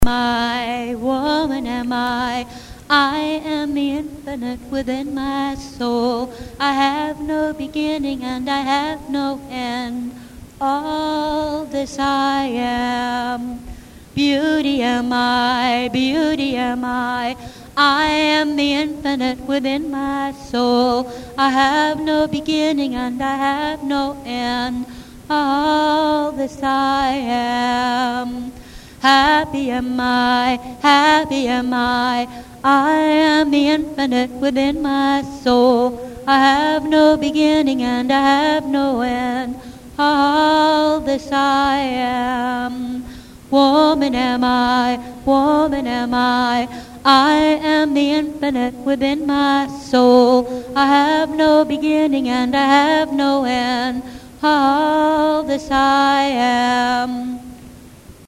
Whenever we gather for ritual, music is always a part of our Circle.
Circle Songs